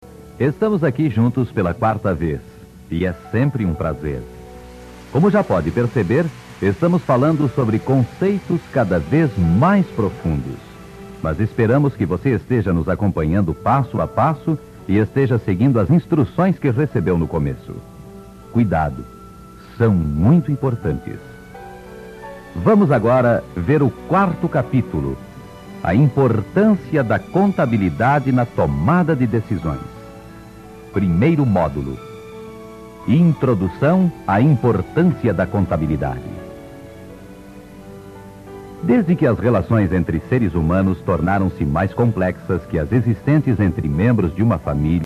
1 CD com a palestra do curso (em mp3)